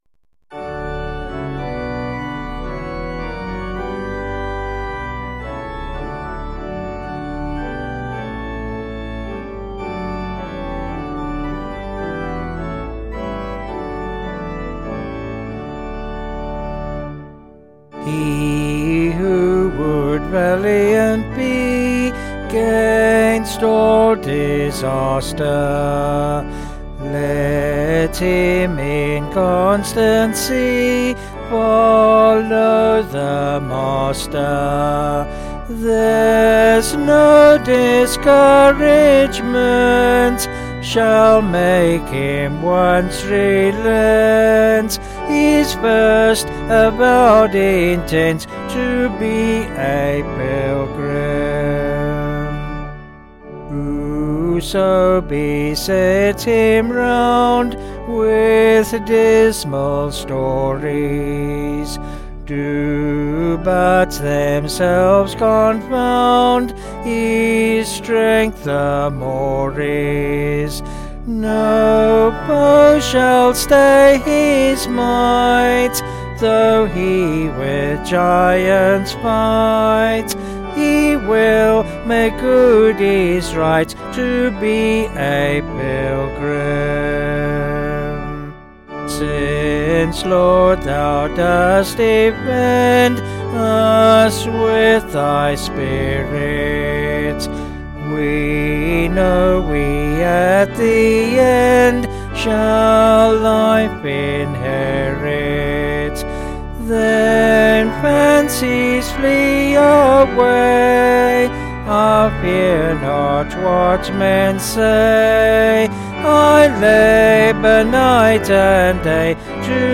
Vocals and Organ   703.8kb Sung Lyrics